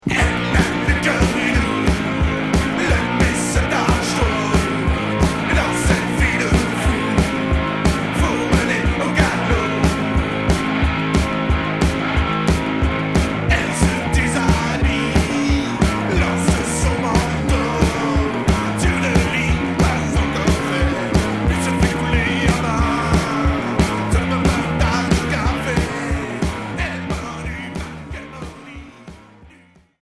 Rock Punk